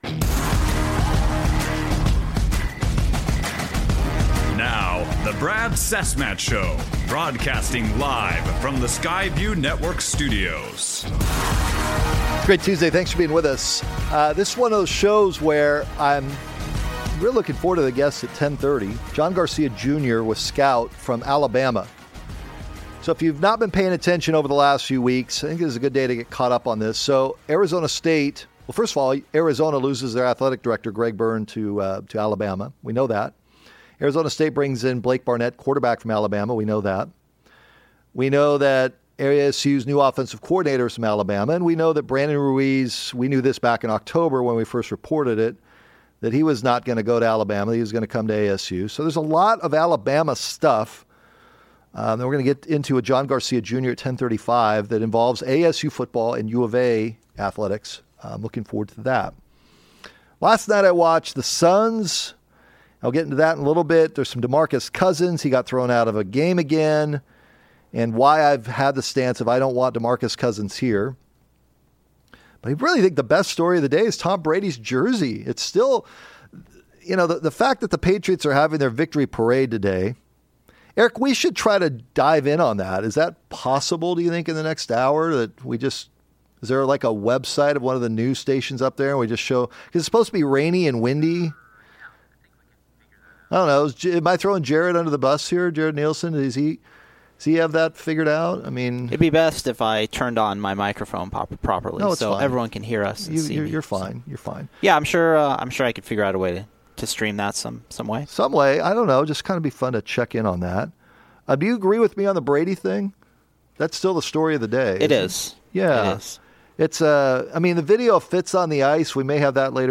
We carried some of New England's snowy ticker-tape parade live on the show.